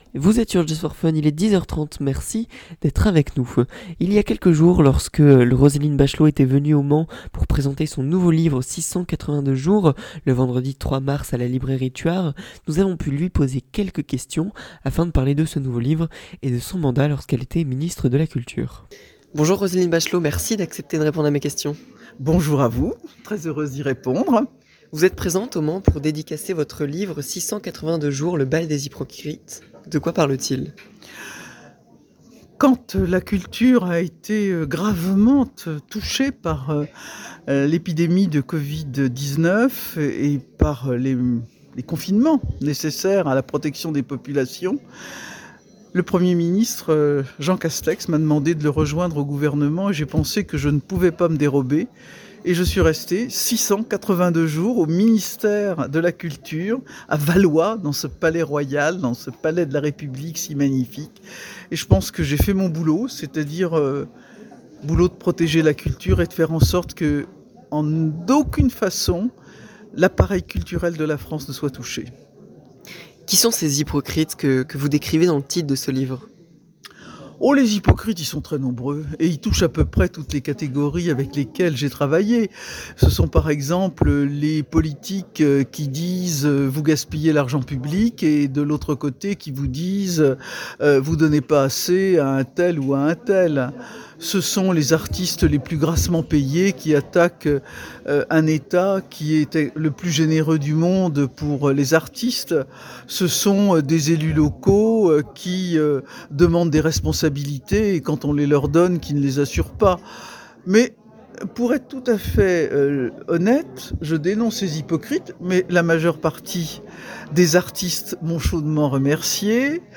ITW de Roselyne Bachelot
Roselyne Bachelot était notre invitée le 12 Mars 2023